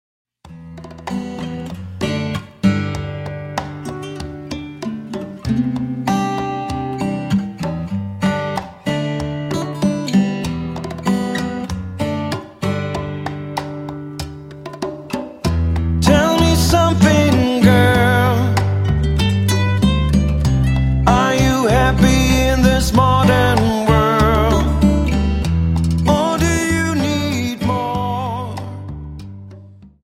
Dance: Rumba 24